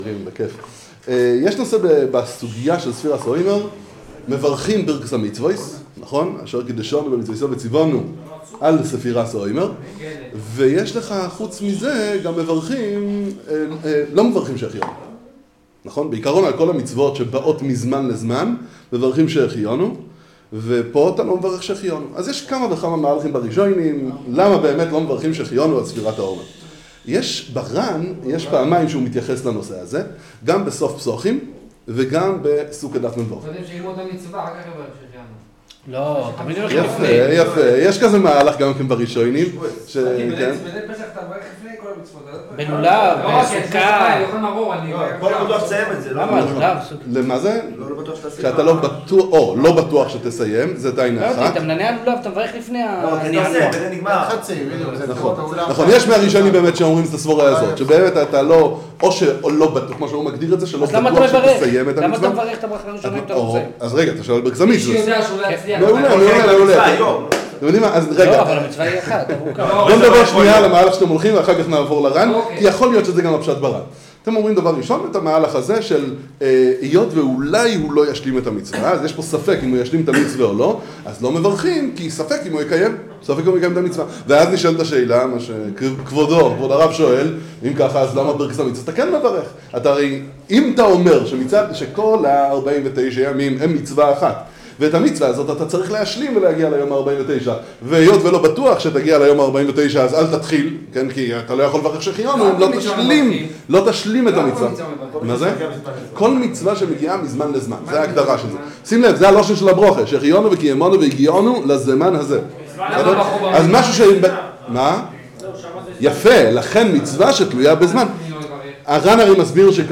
שיעור ודיון בסוגיה